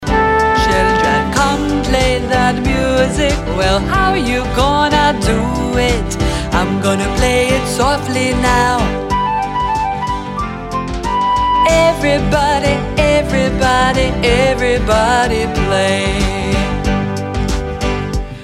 Movement Song Lyrics